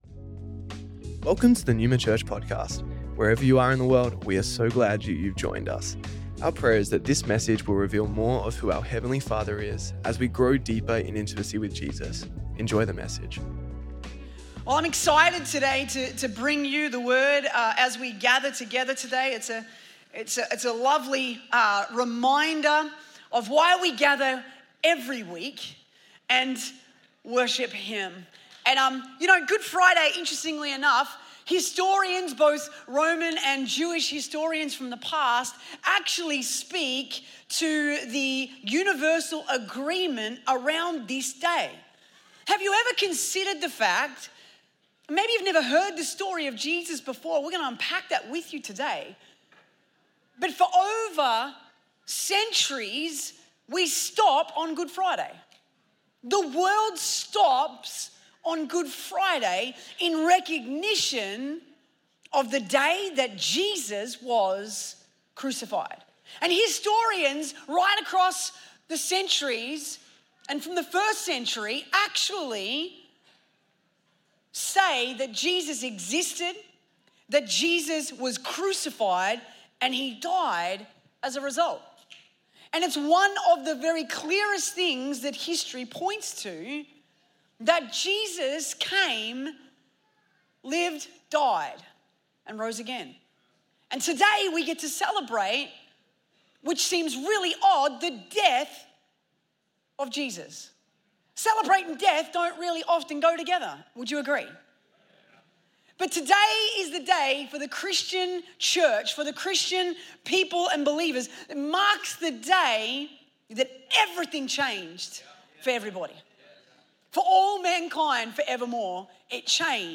Good Friday
Good Friday Originially recorded at Neuma Melbourne City on April 3rd, 2026